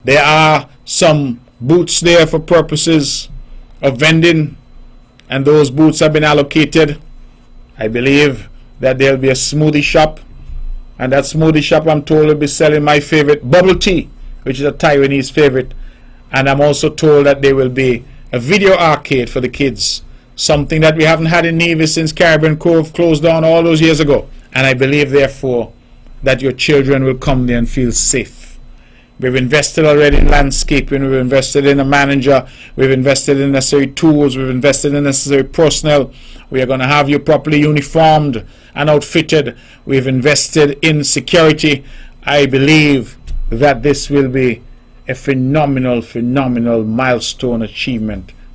Premier Brantley gave these details during the latest edition of the On the Mark Program on what the public can expect at the park on the opening day: